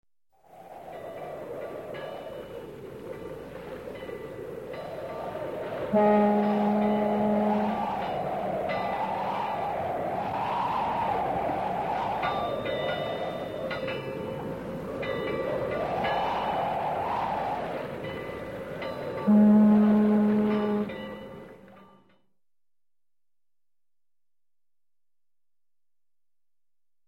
Лес с ветром и скрипящими деревьями Скачать звук music_note Ветер , Шум ветра save_as 4.1 Мб schedule 3:03:00 6 2 Теги: mp3 , ветер , звук , Звуки природы , Лес , Листья , Природа , скрип , скрипы , шум ветра